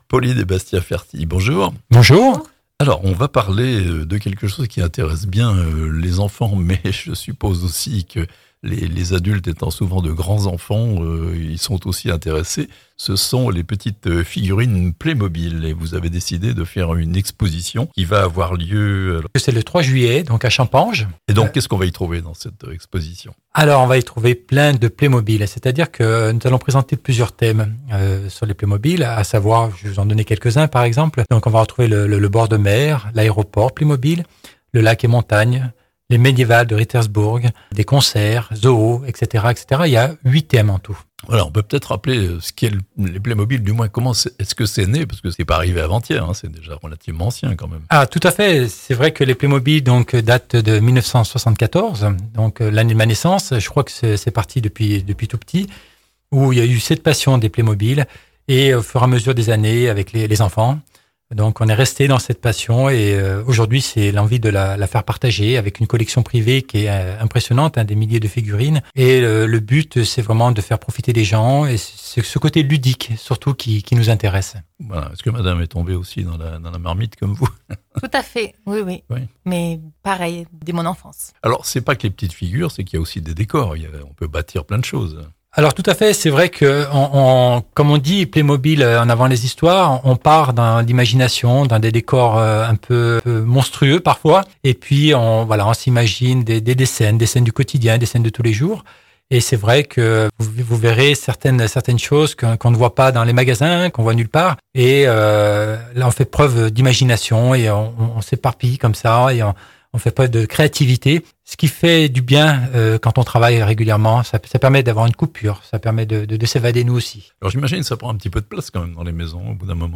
Ils sont venus partager leur passion au micro de La Radio Plus.